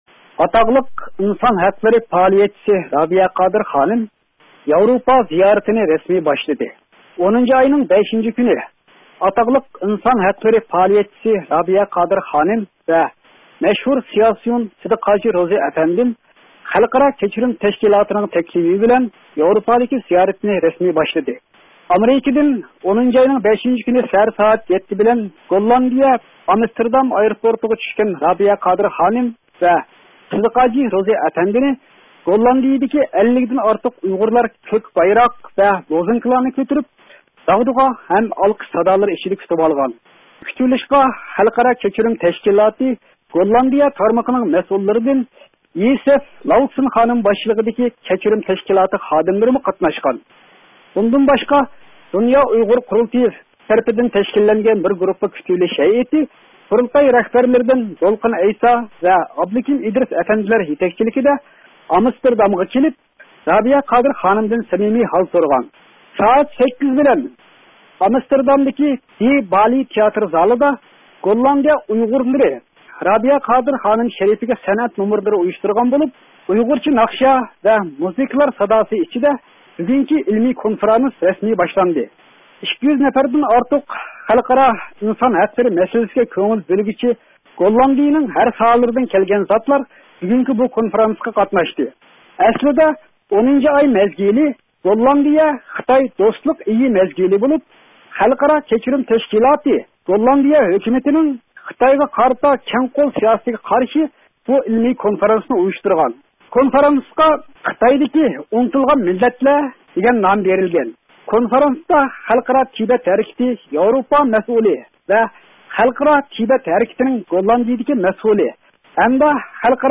مەخسۇس خەۋىرىدىن ئاڭلاڭ